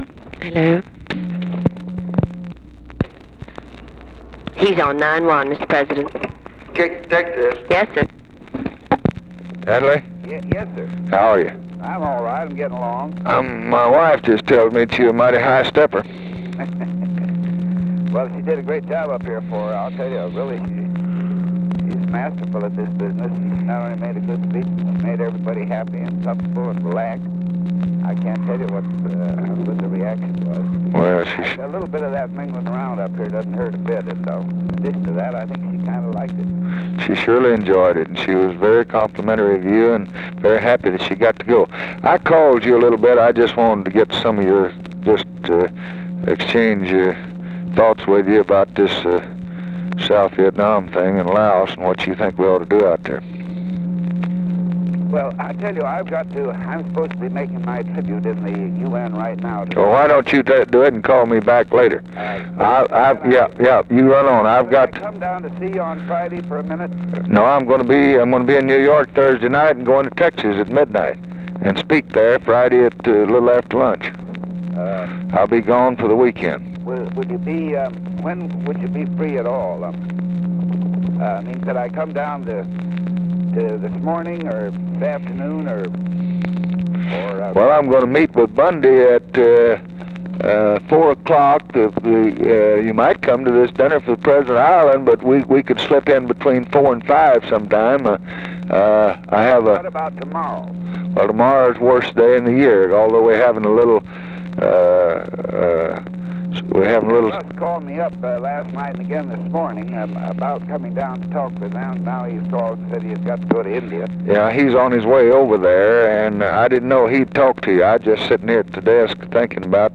Conversation with ADLAI STEVENSON, May 27, 1964
Secret White House Tapes